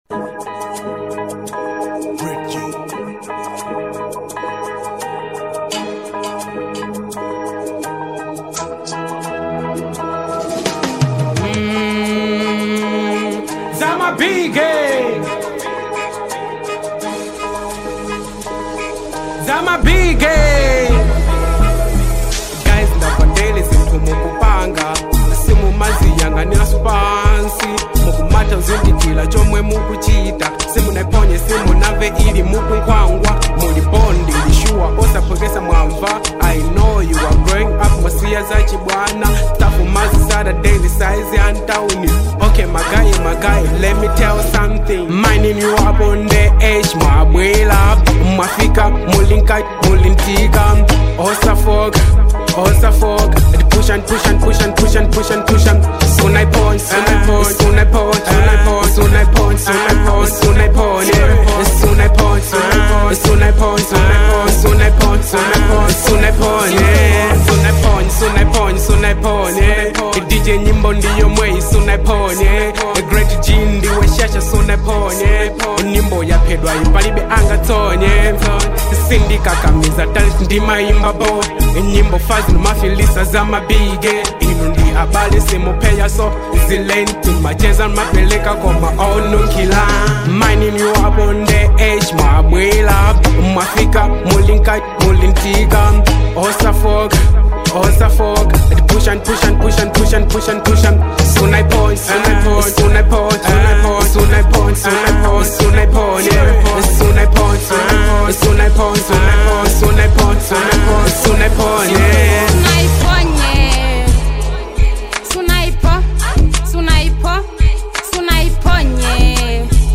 Genre : Hip Hop